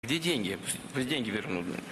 • Качество: высокое
Фраза Путина — а где деньги